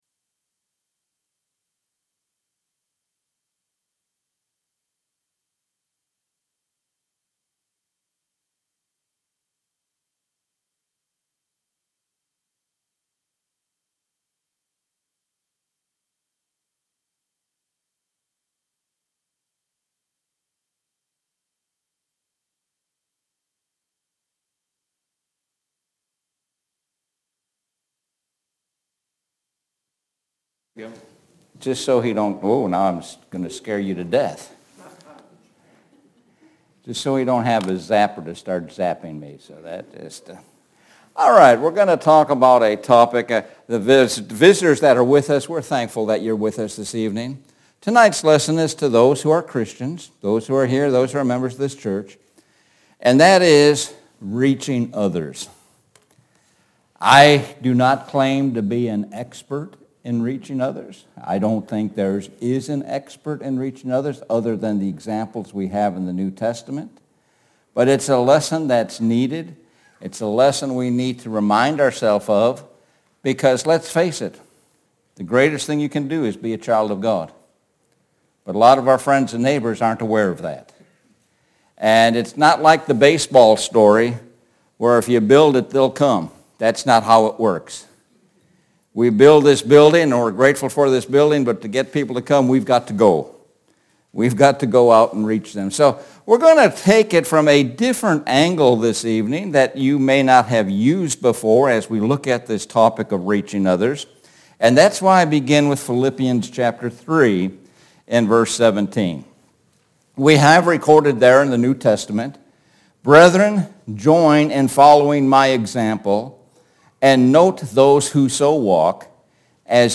Sun PM Bible Sermon – Paul our Example – 12.3.23